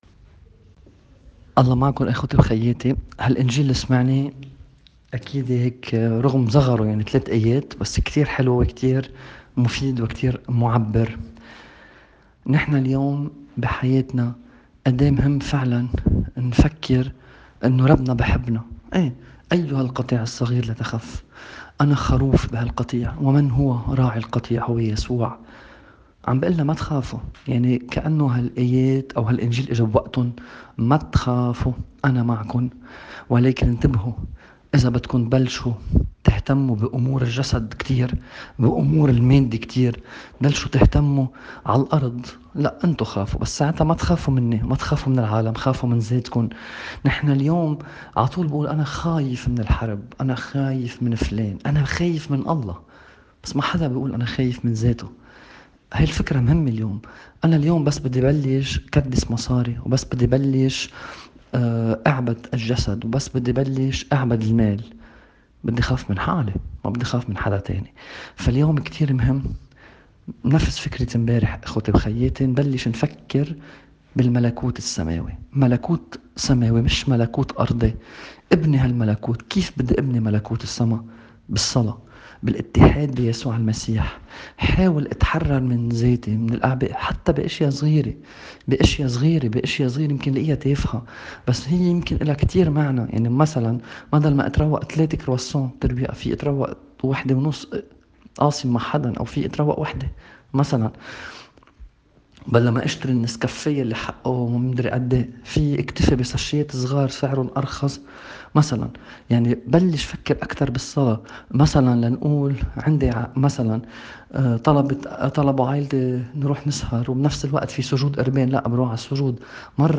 تأمّل بإنجيل اليوم